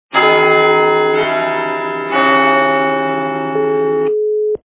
При прослушивании Для СМС - Big Ben качество понижено и присутствуют гудки.